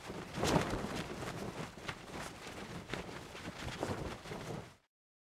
cloth_sail16.L.wav